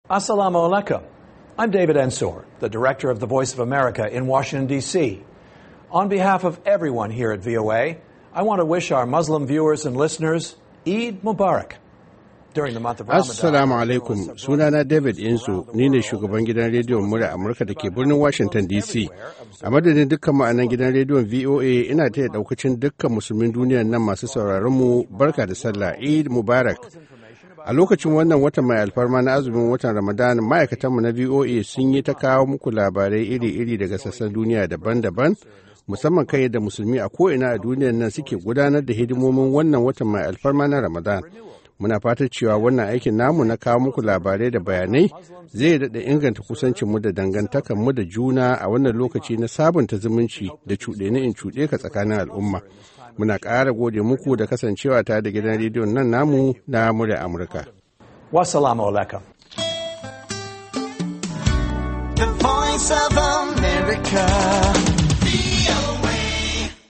Shugaban Gidan Rediyon Muryar Amurka, David Ensor, ya yi wa Musulmin Duniya Barka
Jawabin Salla Daga Shugaban Gidan Rediyon Muryar Amurka - 1:06